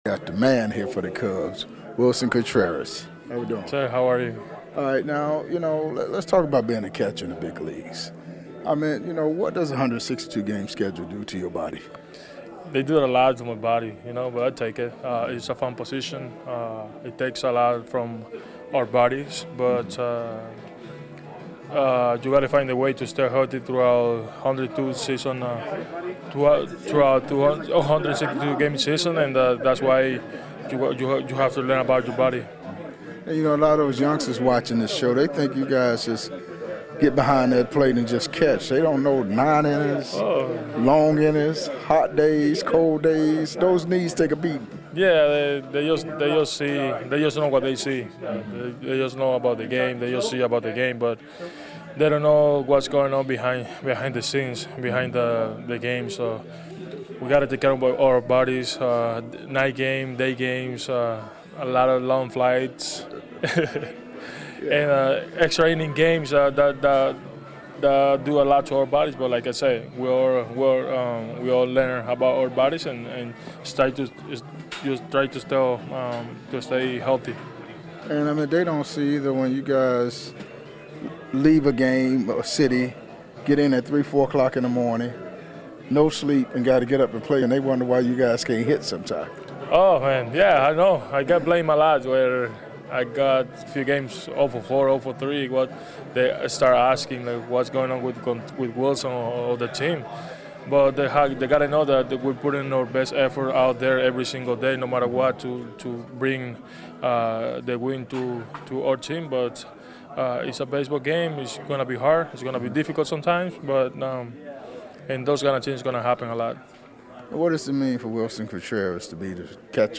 Taking you behind the scenes full uncut and unedited MLB interviews with past and present players